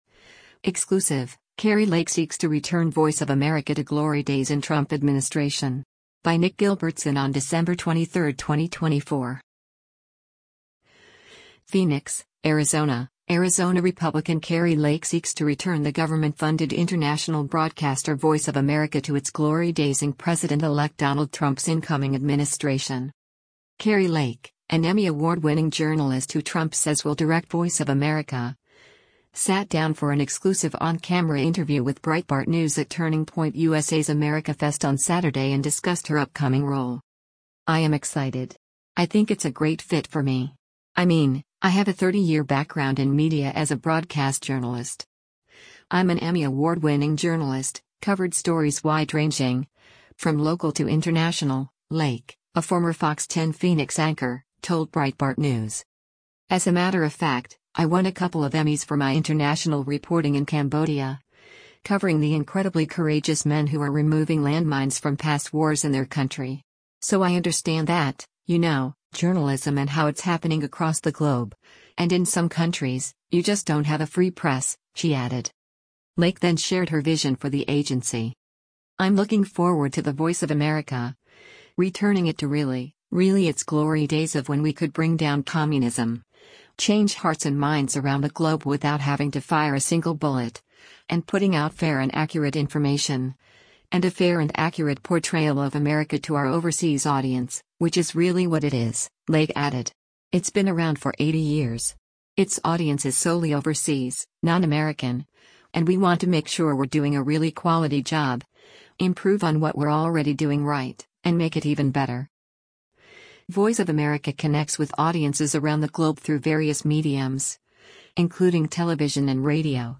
Kari Lake, an Emmy award-winning journalist who Trump says will direct Voice of America, sat down for an exclusive on-camera interview with Breitbart News at Turning Point USA’s AmericaFest on Saturday and discussed her upcoming role.